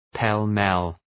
Shkrimi fonetik {pel’mel}